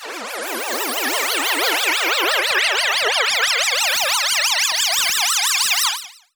CDK Transition 4.wav